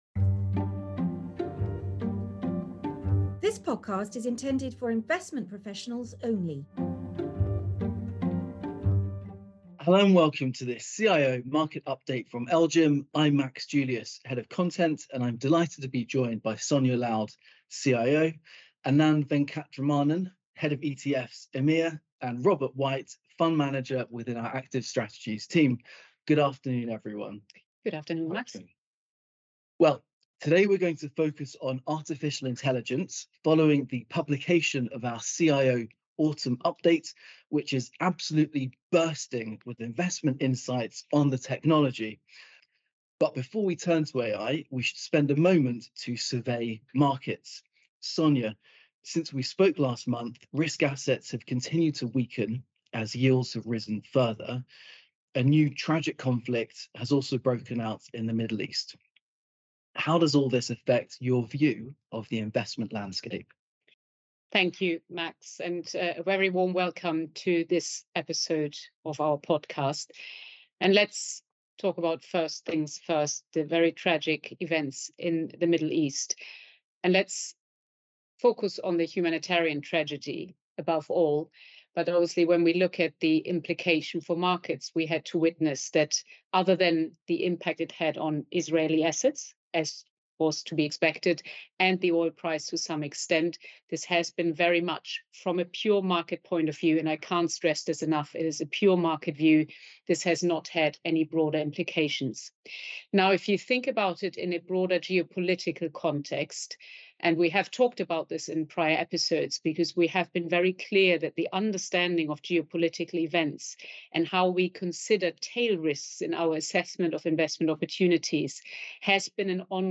The panel also discuss: